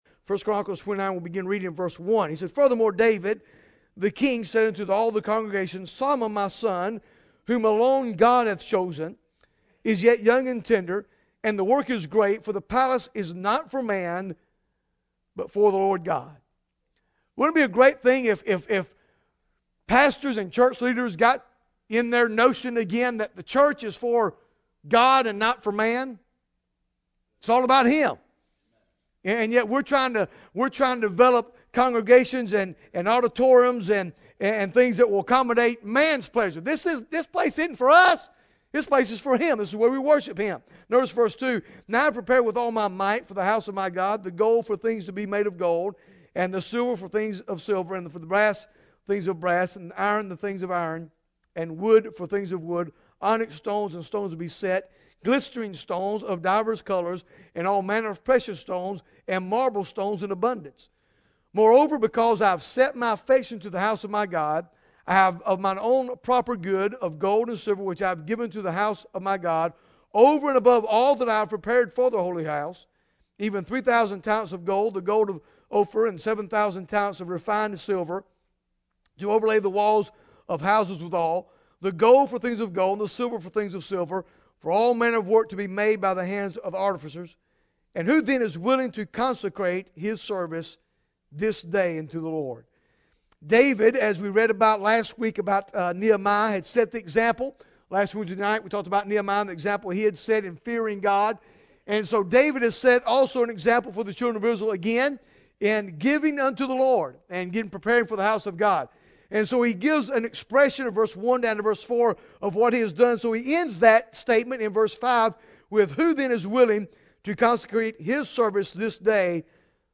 Bible Text: I Chronicles 29 | Preacher